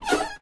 carSuspension1.ogg